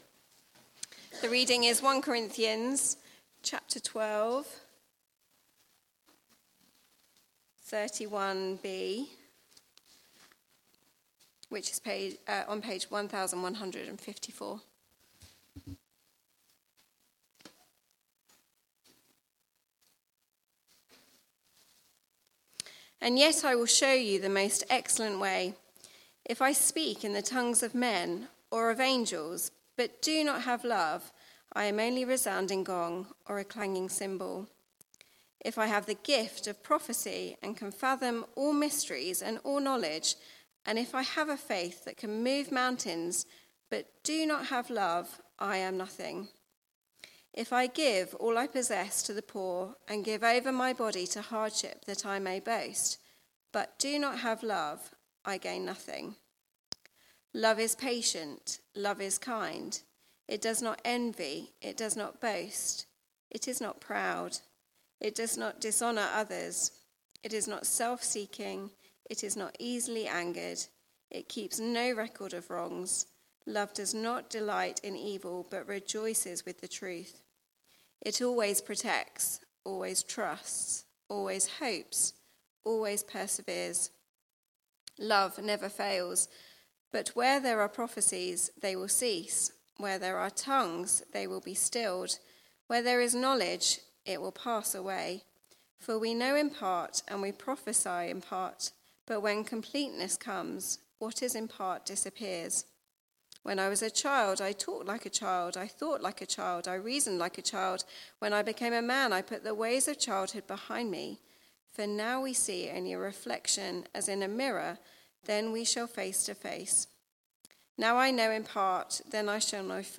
Media for Church at the Green Sunday 4pm
Theme: Sermon